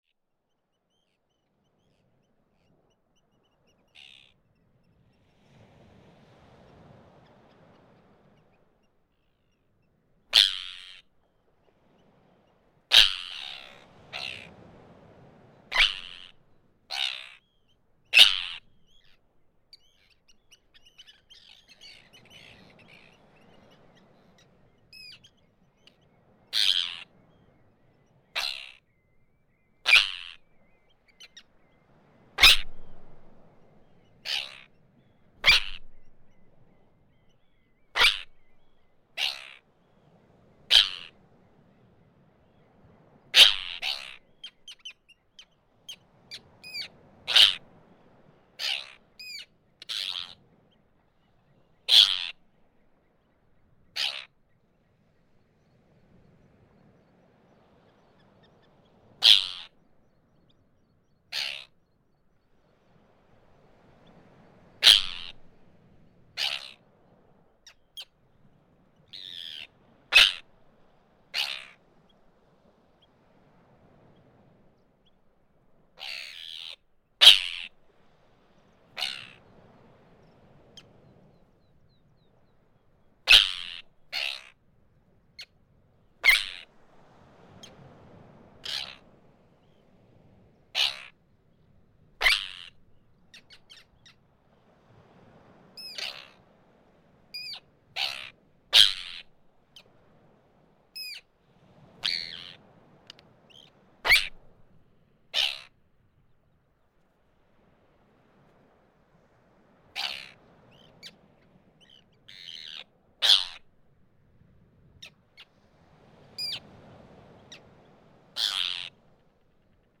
Following recording was recorded nearby the camping side. In the recording an Arctic tern is constantly attacking the fury microphones.